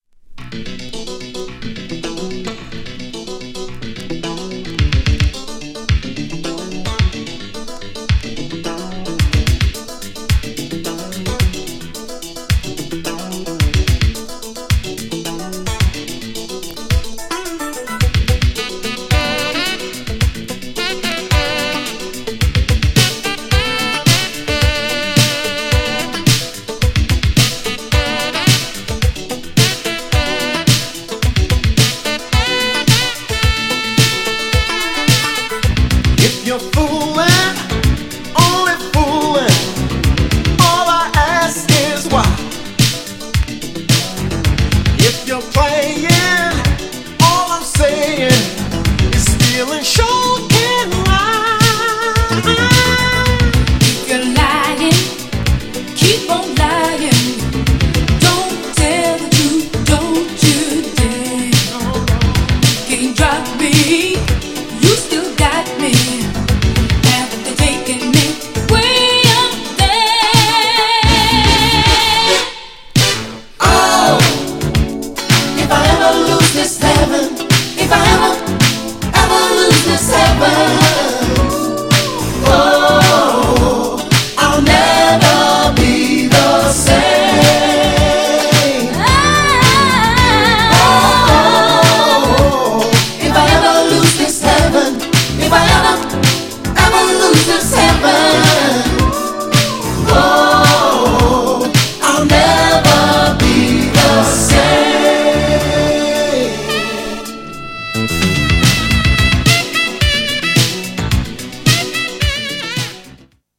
彼らしい都会的でモダンな80'sブラックコンテンポラリーな
GENRE Dance Classic
BPM 101〜105BPM